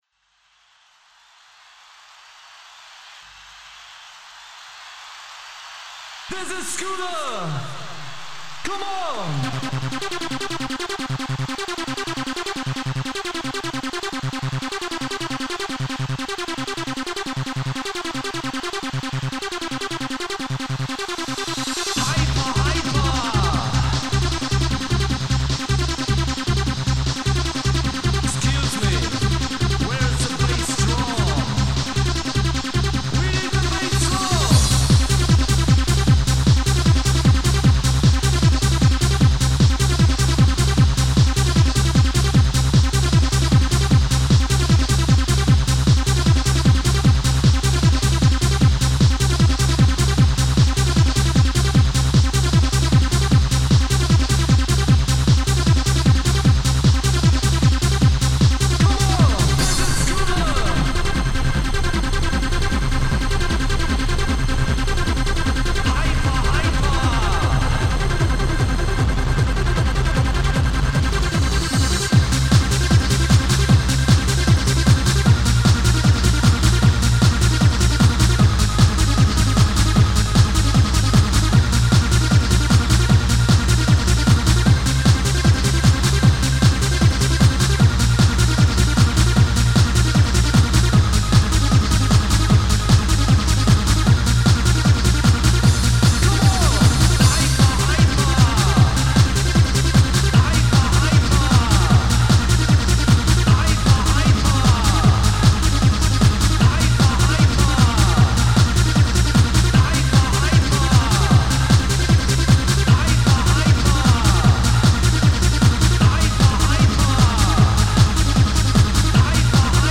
• Quality: 44kHz, Stereo